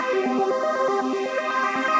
SaS_MovingPad03_120-C.wav